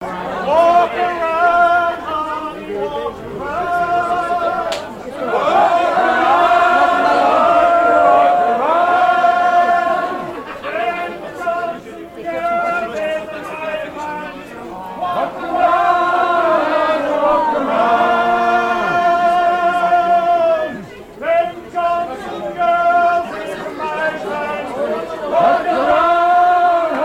Île-d'Yeu (L')
chansons à danser
Pièce musicale inédite